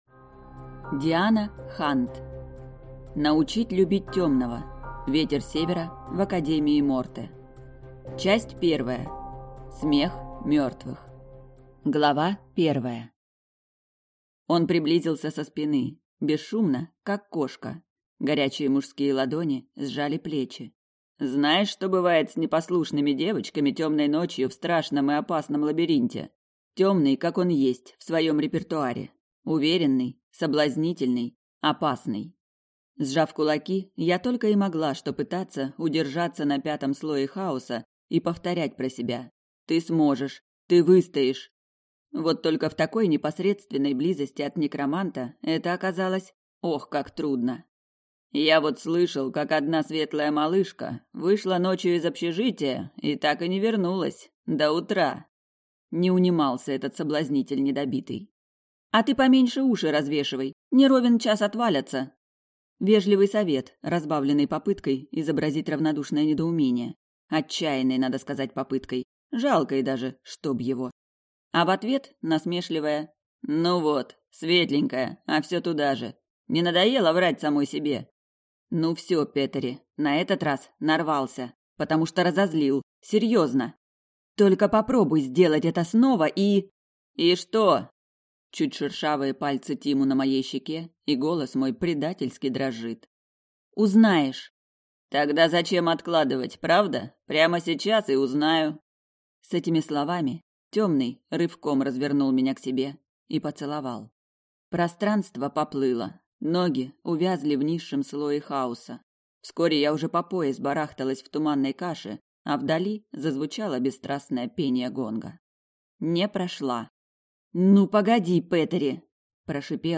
Аудиокнига Научить любить тёмного. Ветер Севера в Академии Морте | Библиотека аудиокниг